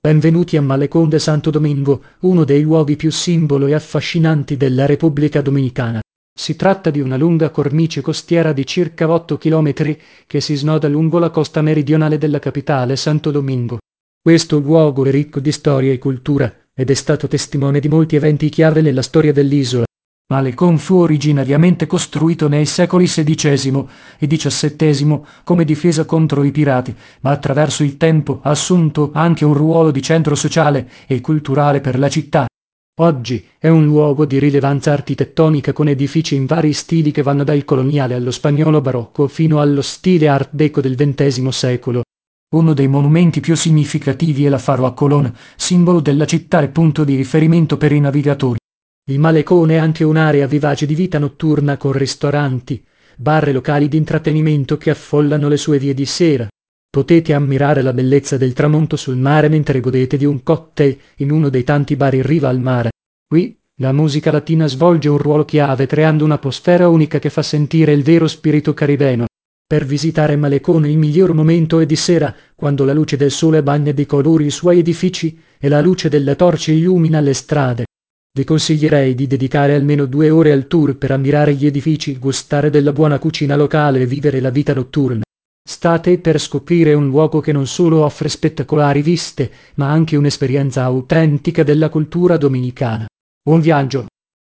karibeo_api / tts / cache / 80b061ea1f263aced45f8f44bcf8f5c4.wav